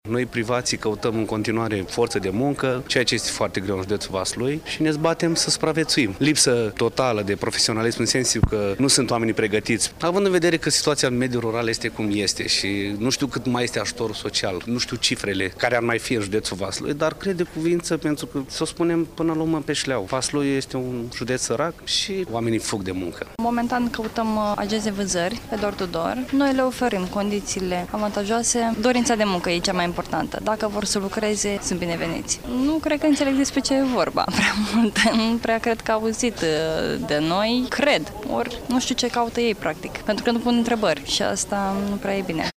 19-oct-rdj-17-voc-pop-angajatori-VS.mp3